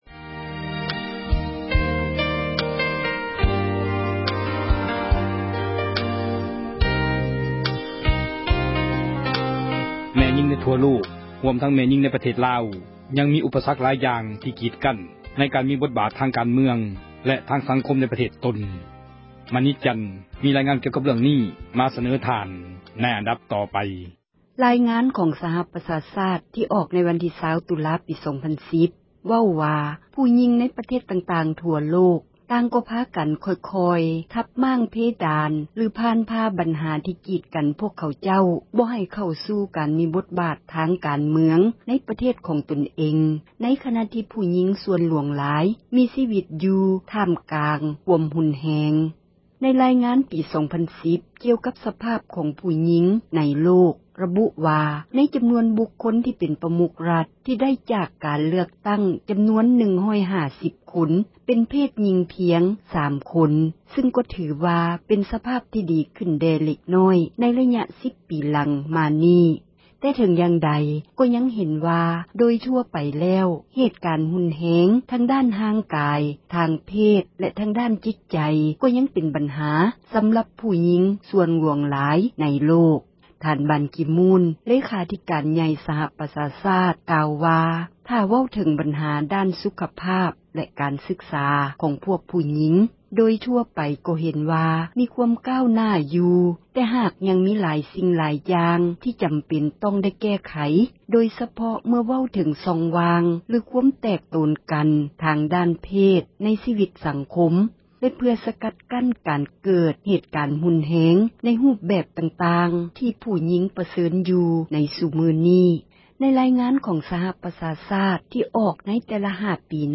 ມີຣາຍງານ ກ່ຽວກັບເຣື່ອງນີ້ ມາສເນີທ່ານ.